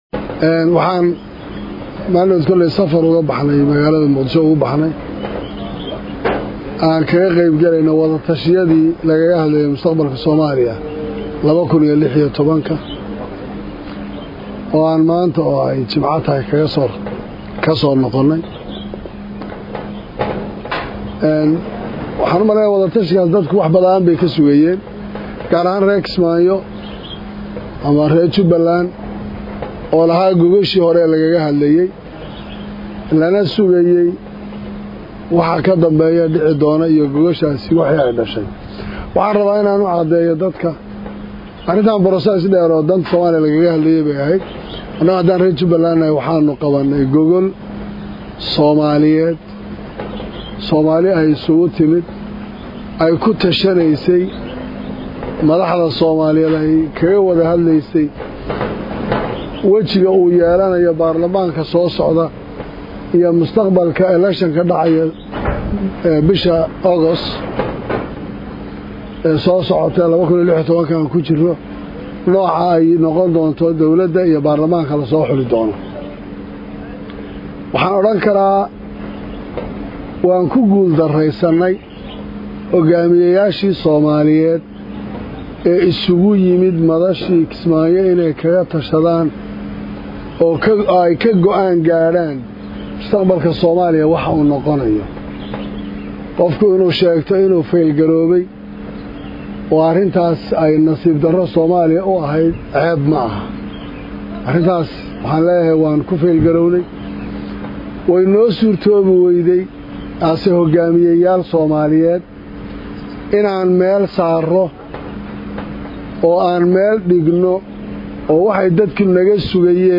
Madaxwaynaha J/land oo shirjaraa,id ku qabtay Kismaayo kana hadlay shirka wadatashiga Qaran ( Dhageyso)
Madaxweynaha Jubbaland Axmed Maxamed Islaan (Axmed Madoobe) ayaa maanta dib ugu soo laabtey magaalada kismaayo ee caasimada KMG ah ee dowlad goboleedka Soomaaliyeed ee Jubbaland waxaana shir jaraa,id uu ku qabtey garoonka diyaaradaha ee magaalada Kismaayo .